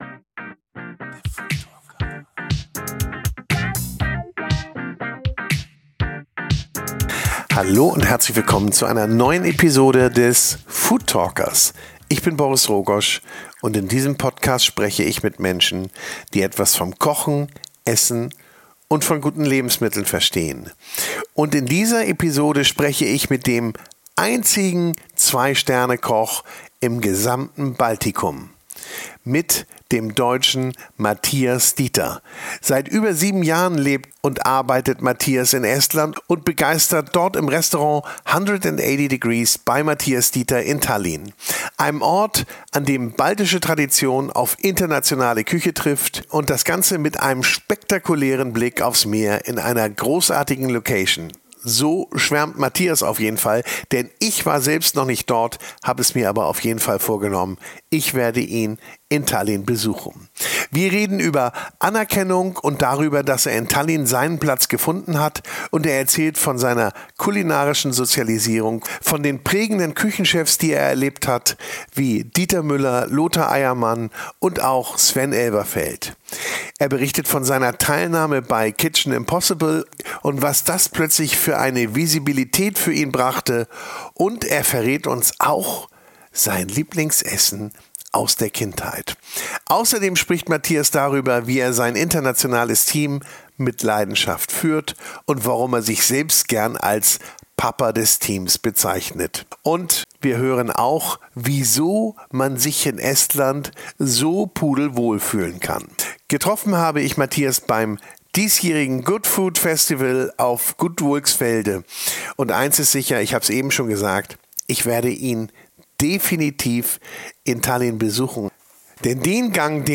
im Gespäch